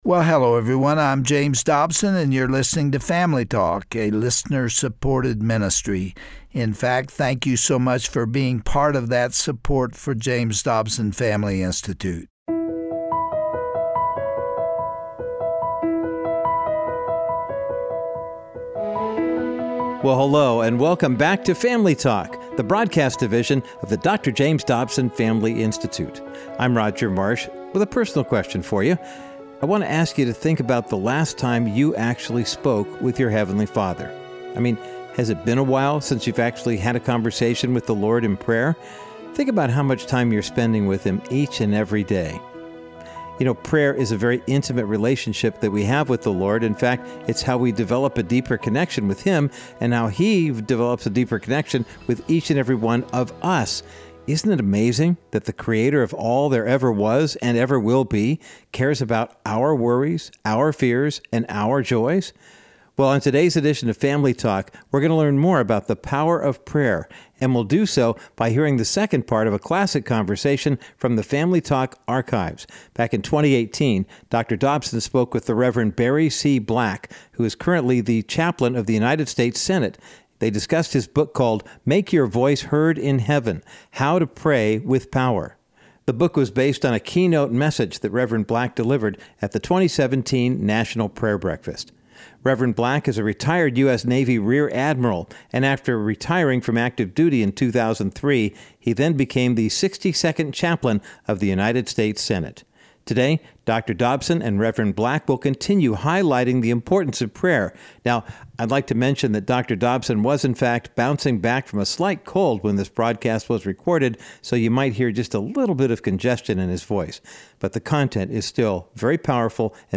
Today on Family Talk, Dr. James Dobson concludes his spirit-led discussion with Chaplain Barry Black, author of Make Your Voice Heard in Heaven. They emphasize that we must humble ourselves, repent, and then we can enter God’s throne room with our requests.